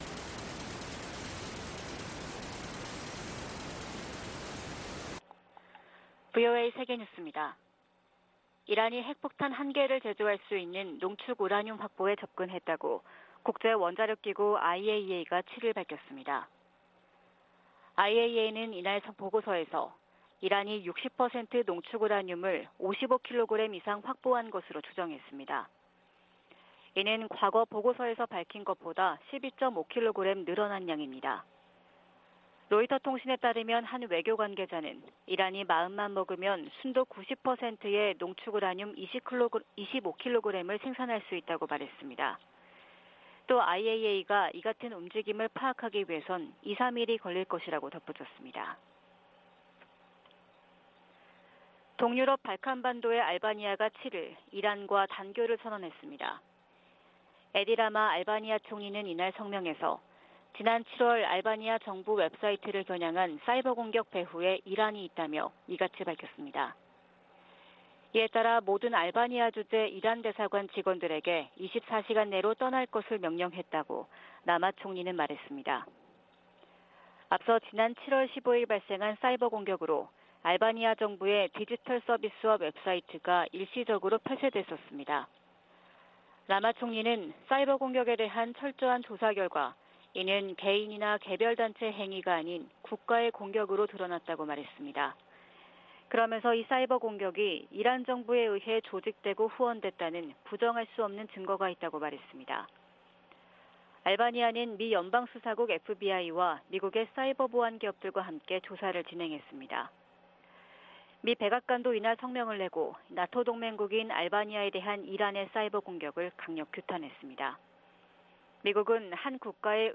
VOA 한국어 '출발 뉴스 쇼', 2022년 9월 8일 방송입니다. 미국과 한국, 일본 북핵 수석대표들이 북한의 도발에 단호히 대응하겠다고 거듭 강조했습니다. 미국 정부가 러시아의 북한 로켓과 포탄 구매에 대해 유엔 안보리 결의 위반이라고 지적했습니다. 유엔이 강제실종과 관련해 북한에 총 362건의 통보문을 보냈지만 단 한 건도 응답하지 않았다며 유감을 나타냈습니다.